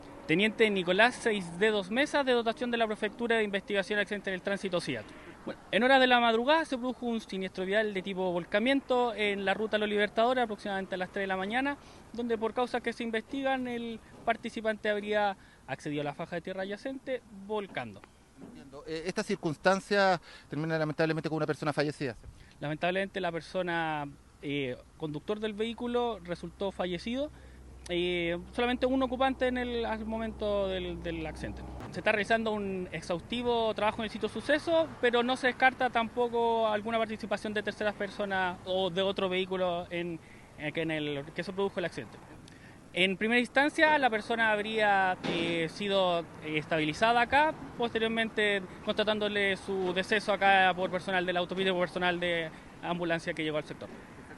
audio-siat-carabineros-colina.mp3-online-audio-converter.com_.mp3